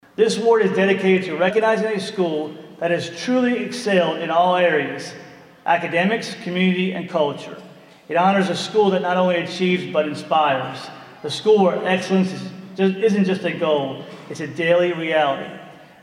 Wednesday night, some employees, their families, and school officials gathered to celebrate several dozen coworkers.